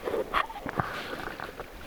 kyhmyjoutsenen ääni?
tuollainen_ilm_joko_laulu_tai_kyhmyjoutsenen_aani.mp3